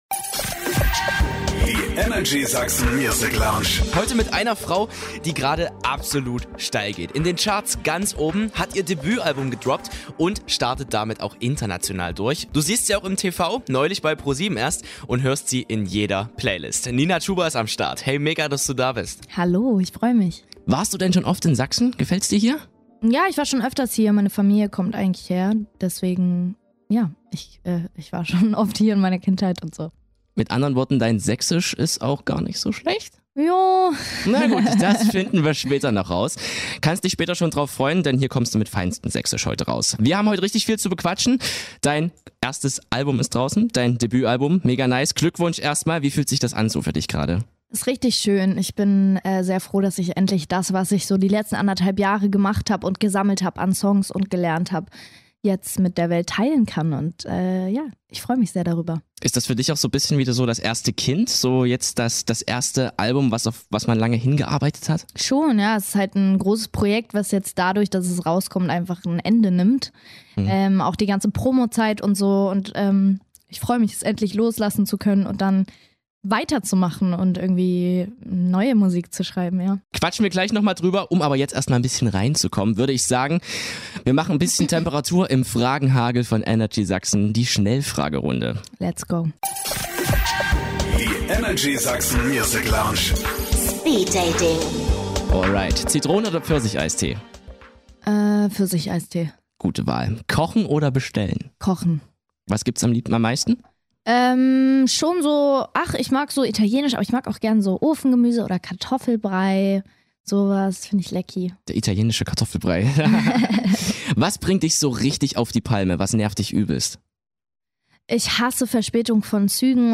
Außerdem hören wir ihr Sächsisch :)